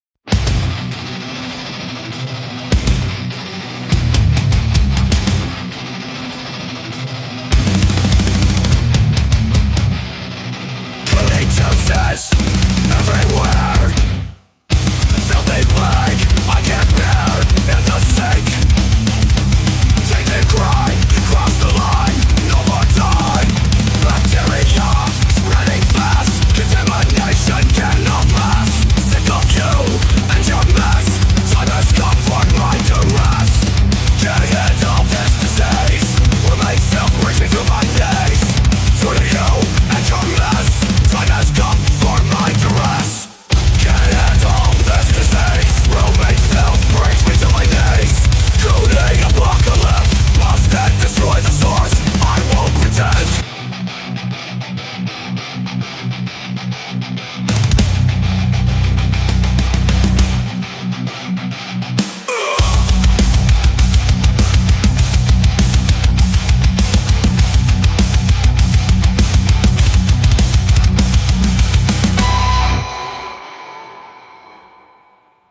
A quick song I put together with help of some friends.
Pure parody and satire.